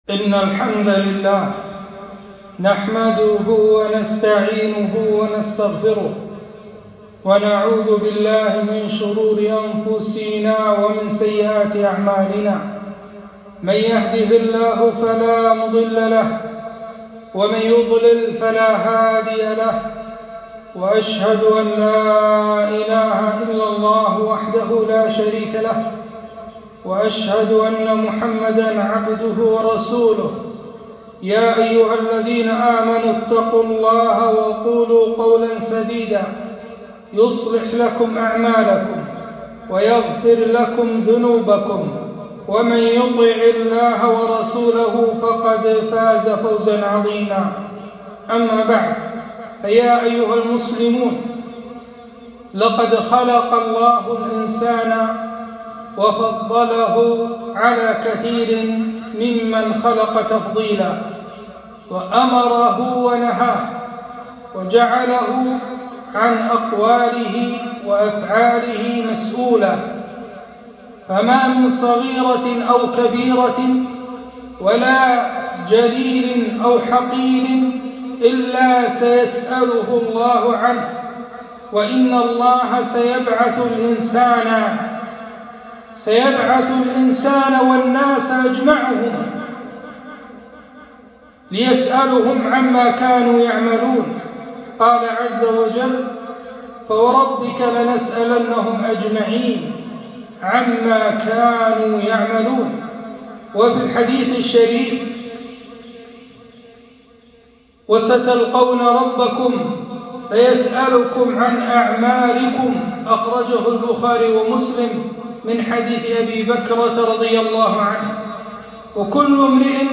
خطبة - وقفوهم إنهم مسئولون